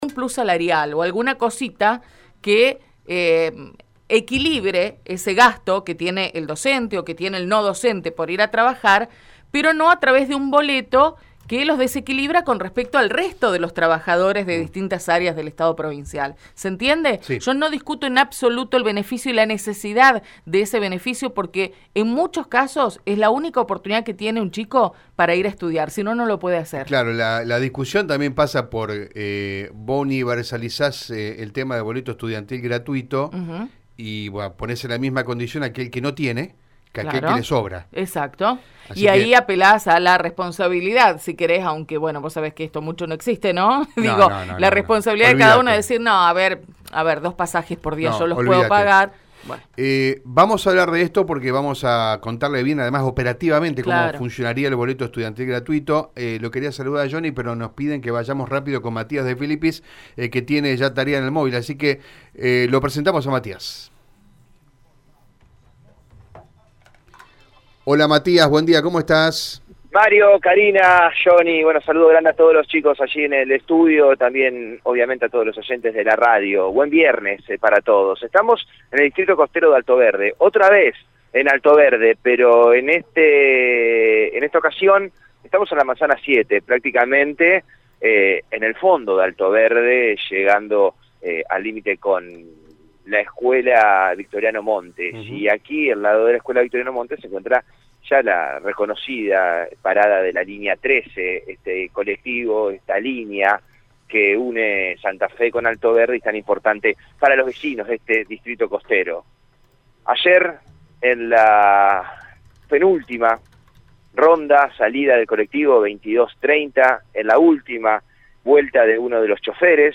Según el móvil de Radio EME, el robo se produjo cerca de las 22.30, cuando el chofer de la linea 13 iniciaba su último recorrido.